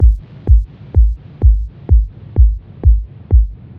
techno 808 reverb kick.wav
Original creative-commons licensed sounds for DJ's and music producers, recorded with high quality studio microphones.
techno_808_reverb_kick_s7e.ogg